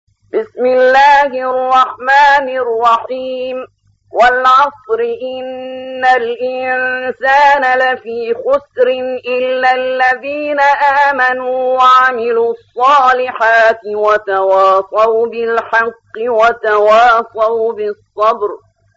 103. سورة العصر / القارئ